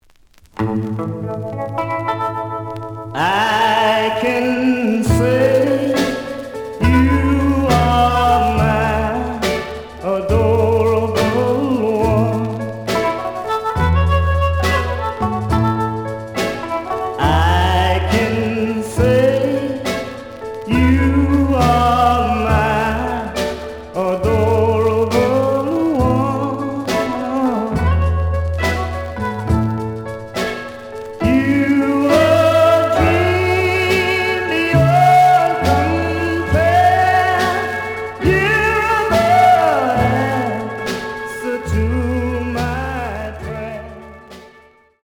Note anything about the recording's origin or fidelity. The audio sample is recorded from the actual item. Some noise on A side.